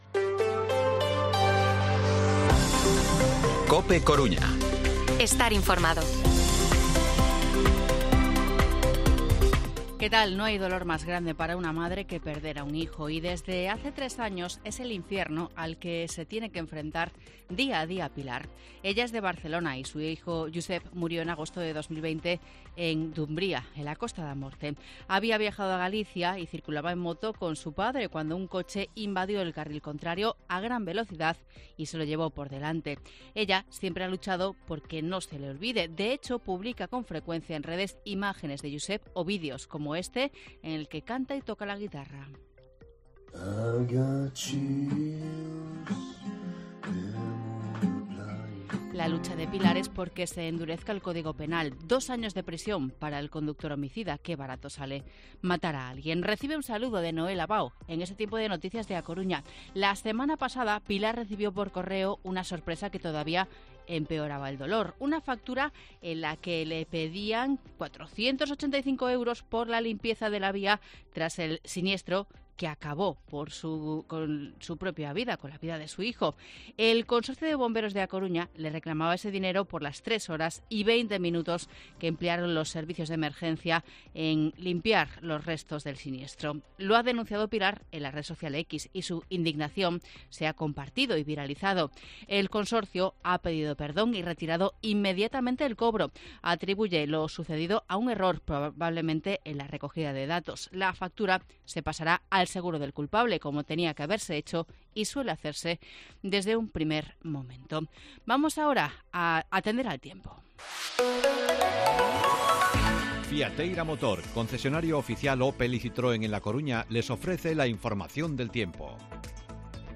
Informativo Mediodía COPE Coruña martes, 10 de octubre de 2023 14:20-14:30